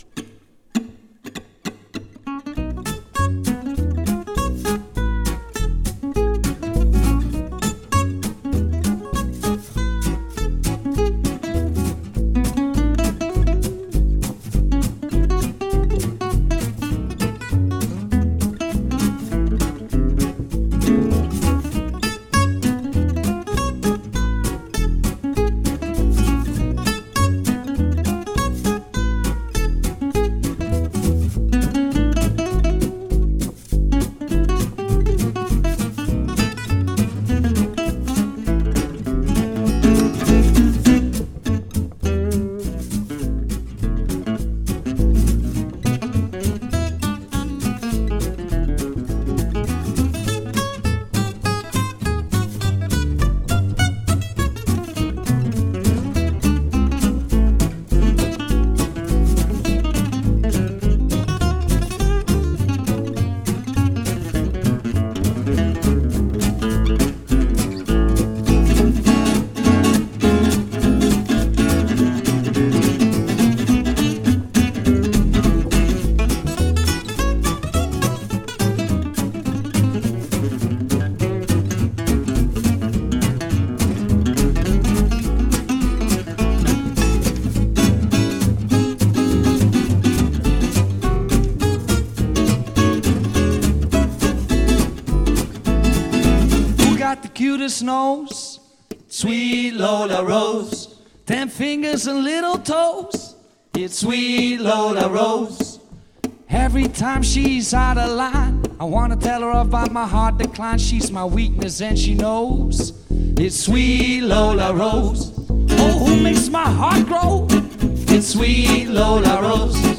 Gypsy Guitar Selmer Style Oval Hole, now with sound!
This is my first gypsy style guitar.
Top- German spruce
Very loud and agressive. But still a warm tone, compared to the ones I played (also 50 years old ones).
Gypsy Sound